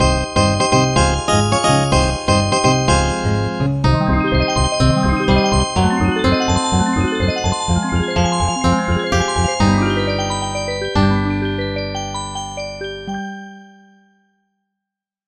The fifth opening theme